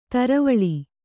♪ taravaḷi